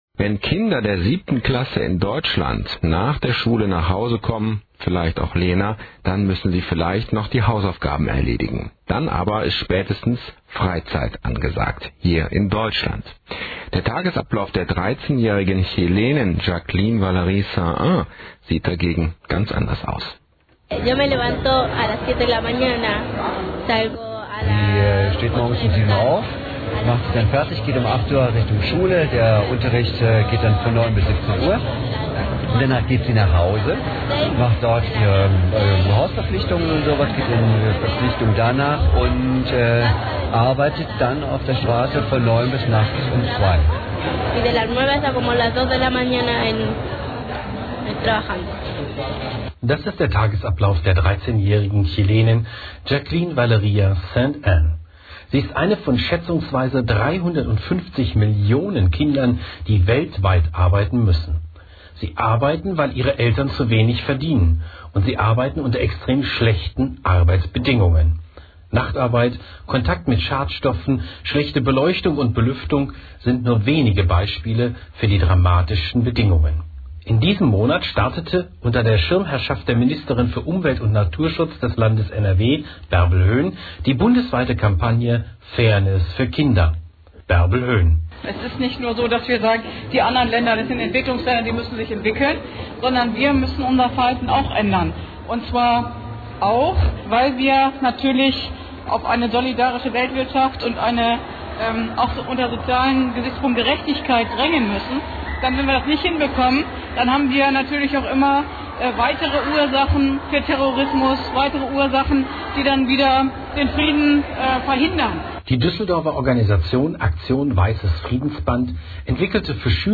Maracatú Nação Movimento aus Recife, Brasilien spielt MARACATU, eine Form der Karnevalsmusik in Brasilien. Voluminöse Trommeln, große und kleine Perkussionsinstrumente spielen Rhythmen, die von ersten Synkope an in die Beine gehen.